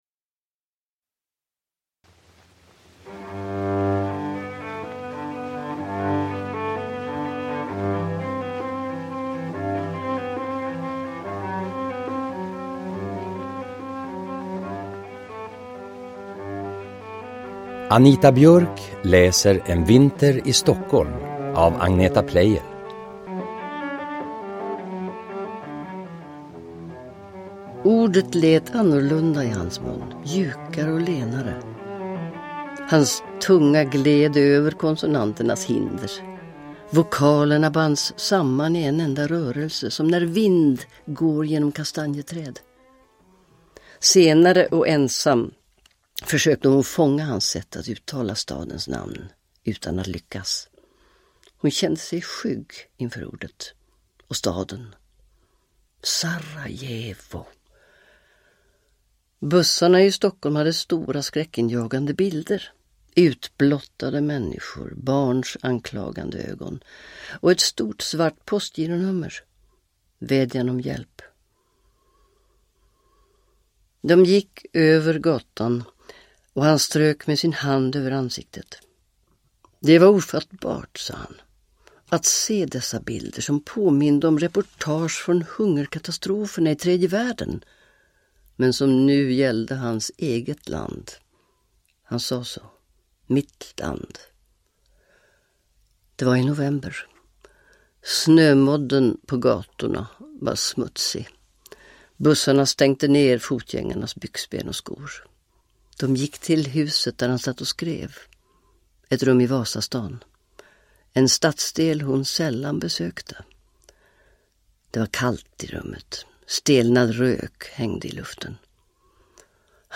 En vinter i Stockholm – Ljudbok
Uppläsare: Anita Björk